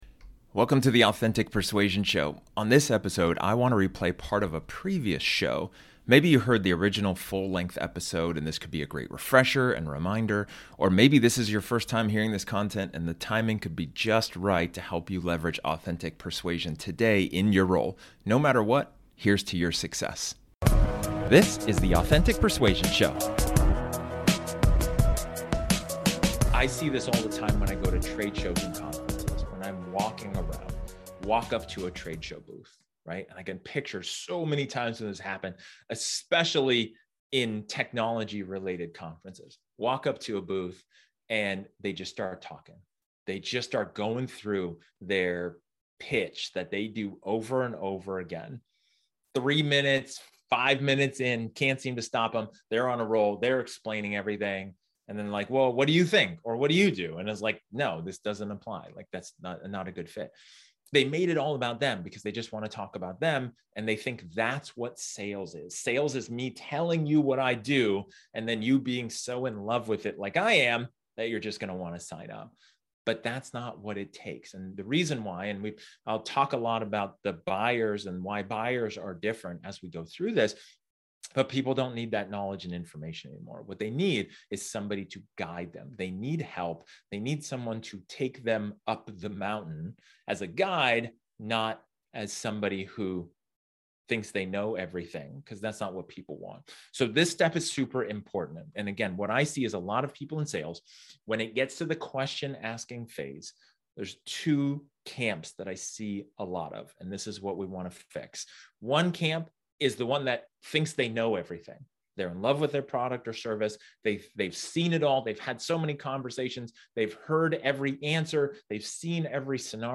This episode is an excerpt from one of my training sessions where I talk about effective empathy.